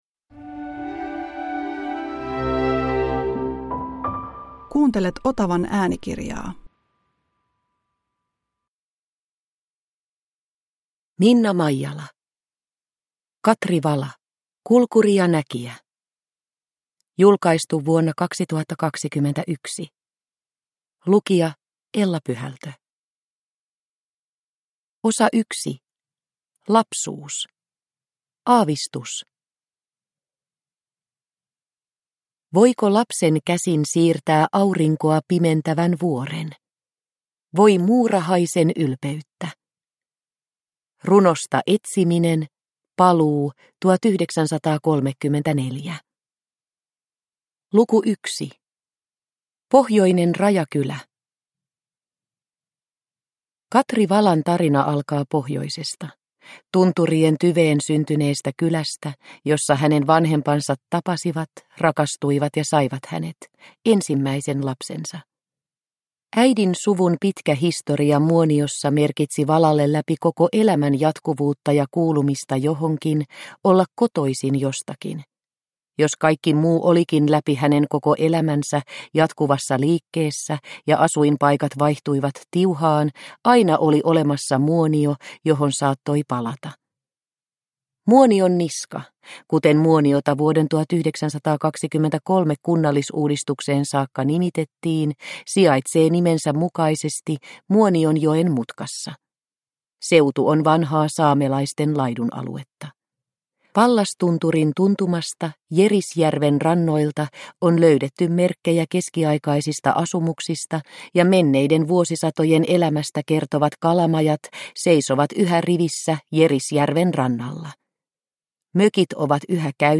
Katri Vala – Ljudbok – Laddas ner